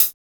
HIT CHH 2.wav